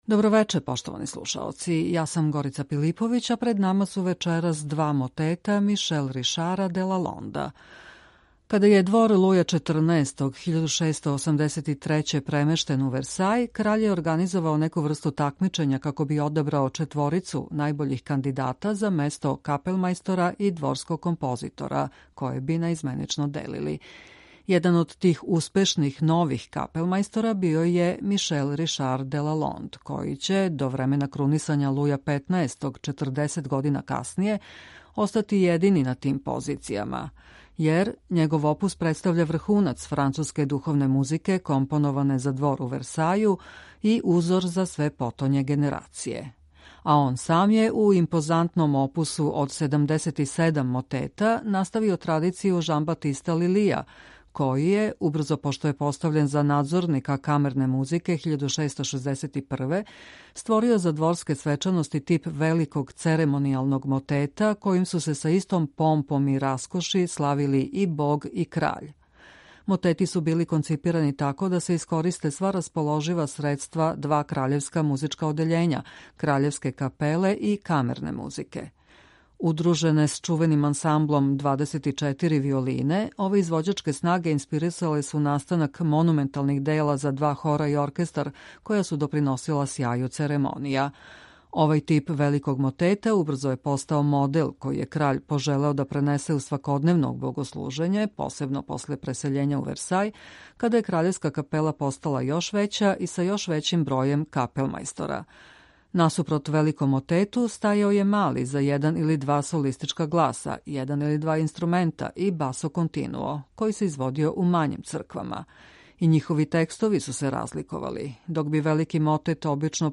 У емисији Висине можете слушати два мотета француског барокног аутора Мишел-Ришара де Лалонда.